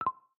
VideoRecordEnd.ogg